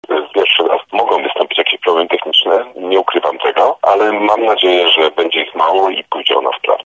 Mówi przewodniczący Rady Miasta Tarnobrzega, Bogusław Potański.